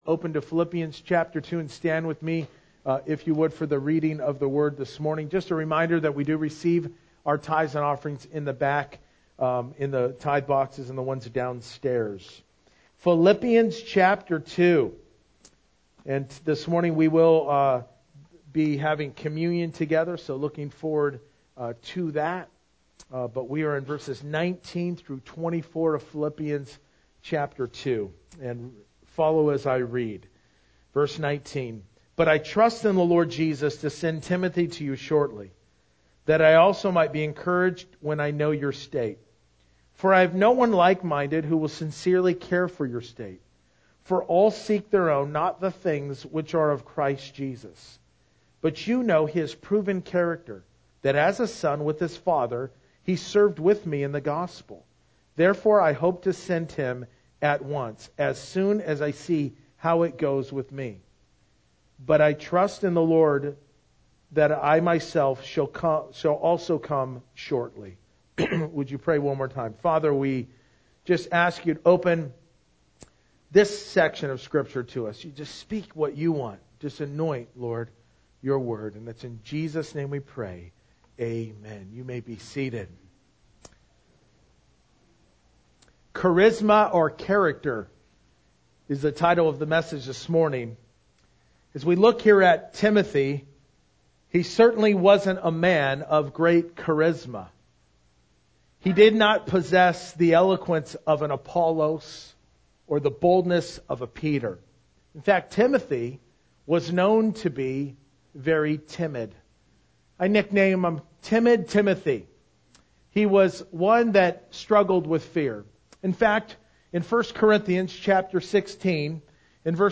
Verse by Verse-In Depth